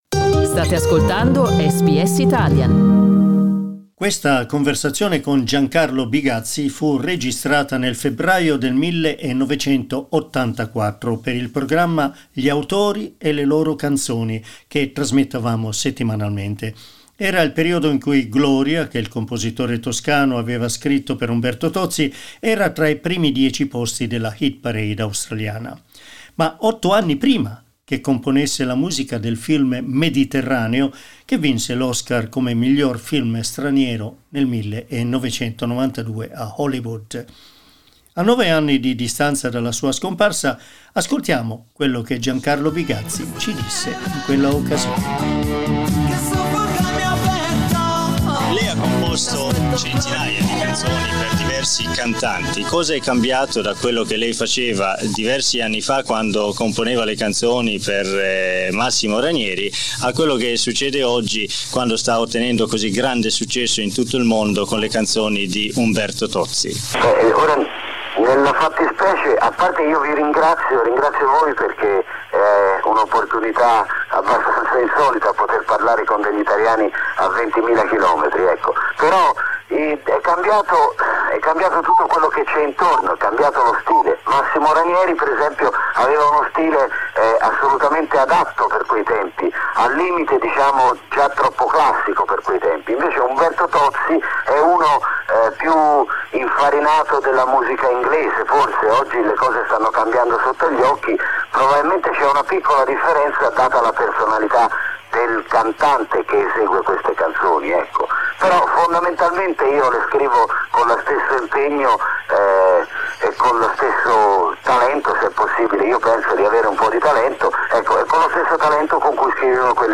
In our archives we found this conversation with Giancarlo Bigazzi, one of the most important lyricists and composers of Italian pop music of all time. It was recorded in February 1984, when his song "Gloria" by Umberto Tozzi was on the Hit Parade around the world, including Australia.
Giancarlo Bigazzi was our guest on the program Authors and their songs which aired on February 13, 1984.